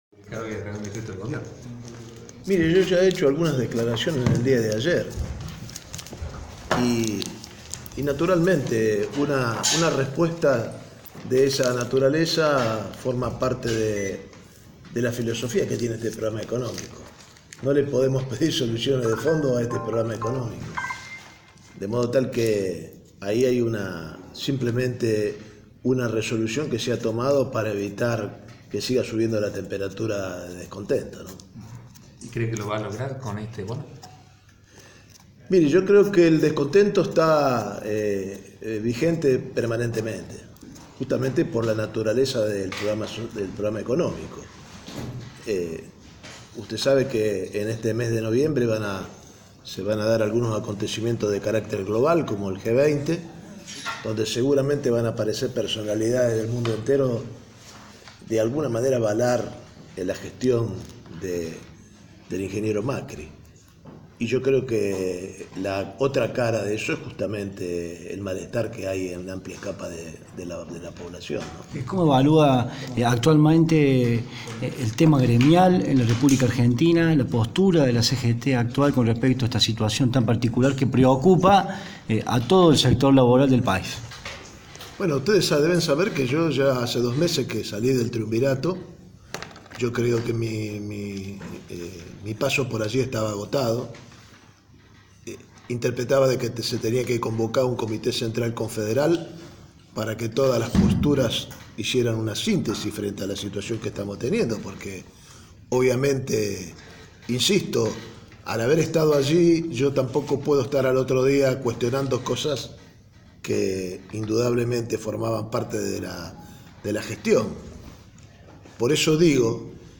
El secretario general de la CATT, Juan Carlos Schmid, y ex triunviro de la Confederación General del Trabajo, reflexionó sobre la situación actual del país, su salida de la CGT y cuál considera que es el rumbo que debe adoptar la central. El «Capitán» realizó declaraciones durante su visita a la ciudad de Río Cuarto, Córdoba.